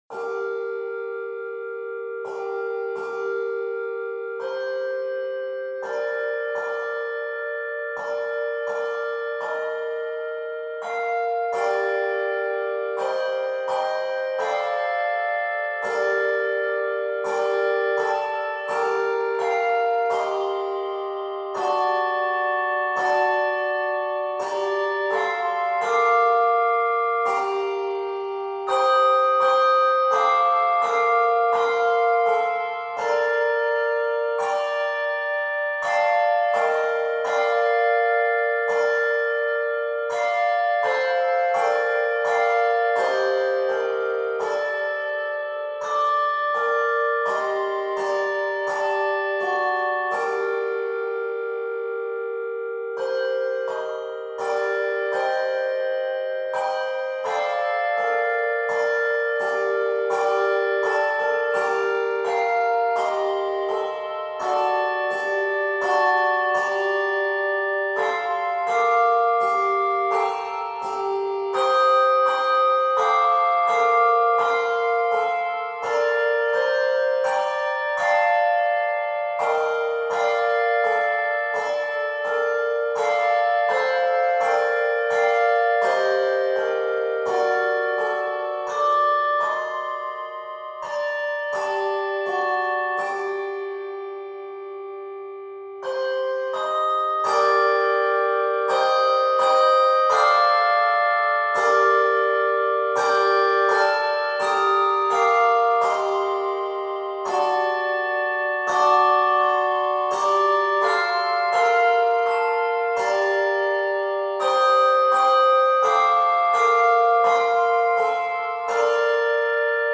Lutheran passion hymn tune